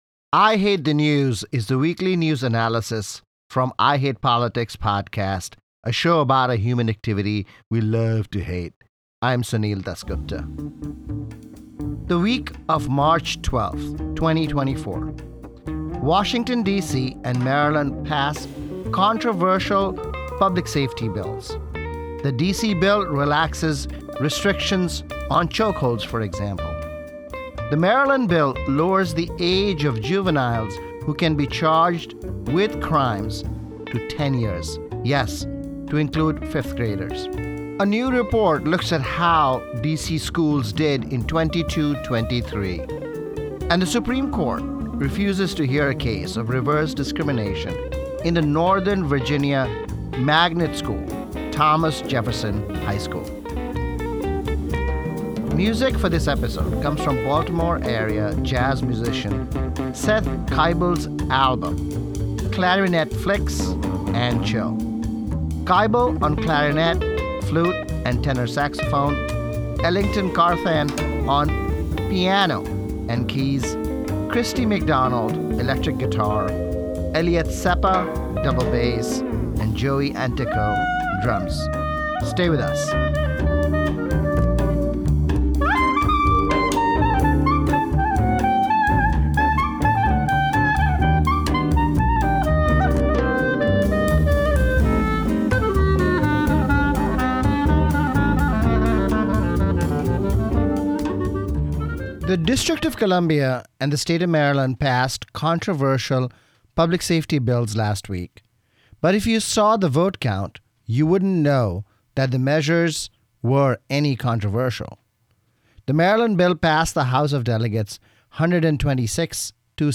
The weekly news analysis from I Hate Politics: Washington DC and Maryland pass controversial public safety bills that respectively relaxes restrictions on chokeholds and lowers the age of those who can be charged for non-violent crimes to include fifth graders. New report looks at how DC schools did in 2022-23; and the Supreme Court refuses to hear a case of Asian discrimination in Thomas Jefferson High School in Northern Virginia.